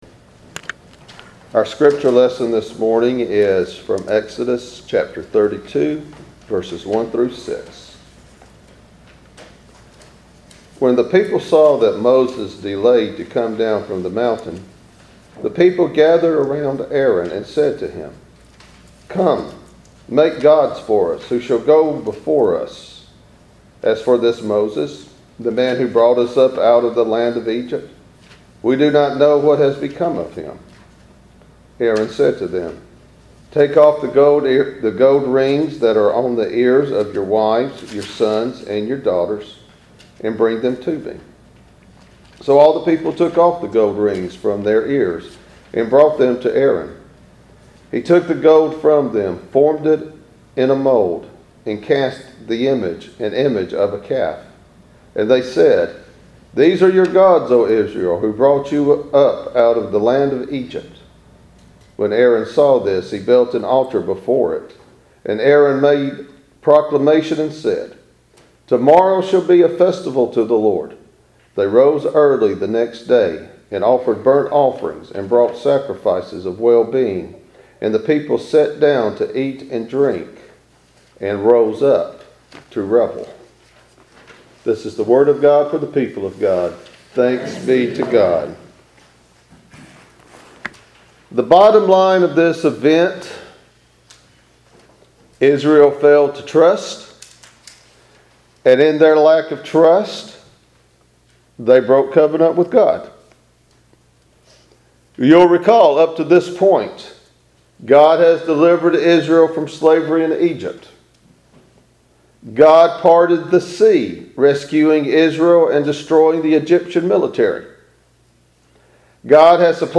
Walker Church SERMONS